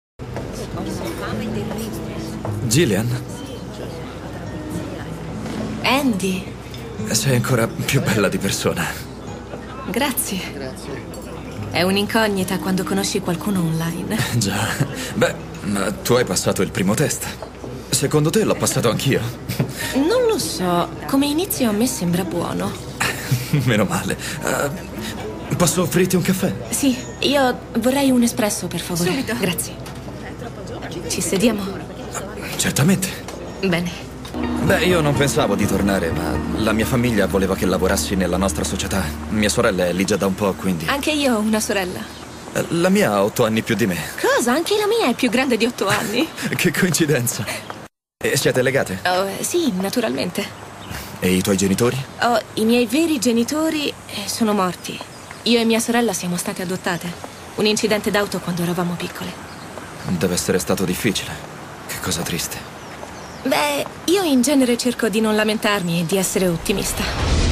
voce di